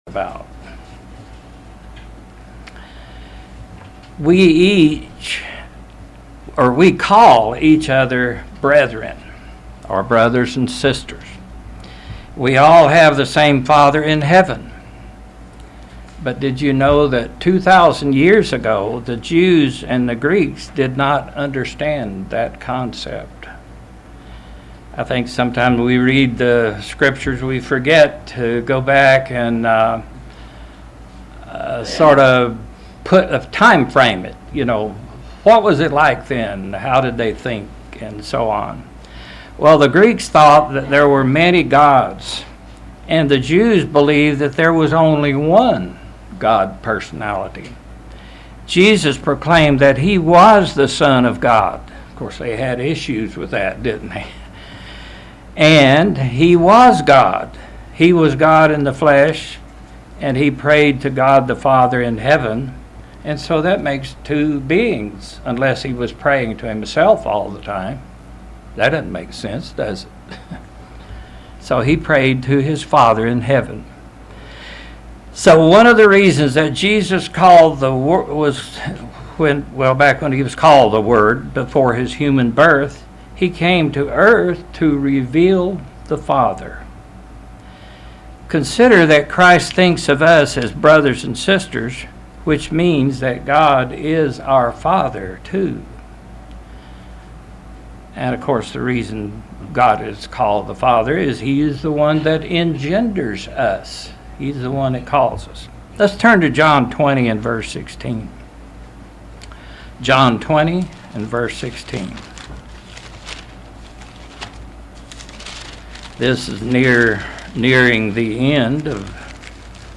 Sermons
Given in London, KY